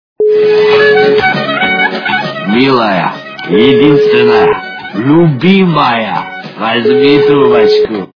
» Звуки » Смешные » Грузин - Милая, единственная, возьми трубочку!
При прослушивании Грузин - Милая, единственная, возьми трубочку! качество понижено и присутствуют гудки.